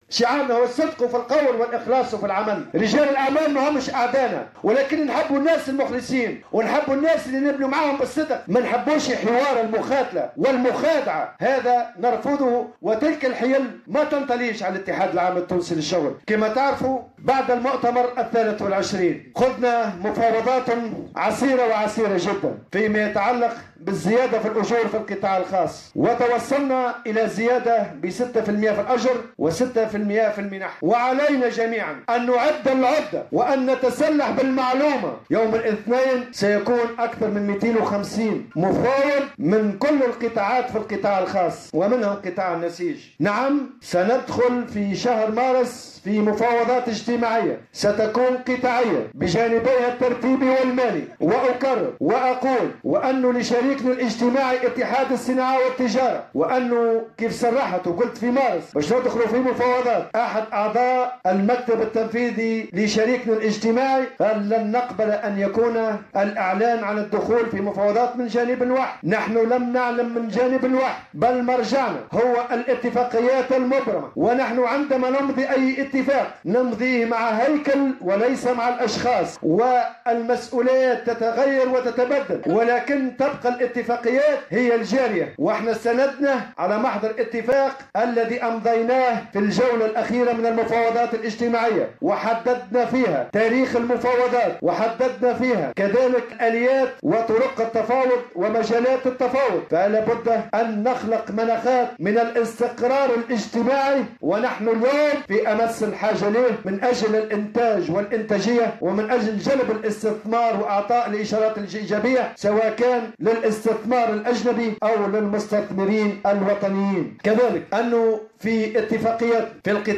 Le début des négociations est prévu pour début mars, selon l'accord préalablement signé entre l'UGTT et l'UTICA, a t-il rappelé en marge du congrès de la fédération générale du textile, de l'habillement, du cuir et de la chaussure.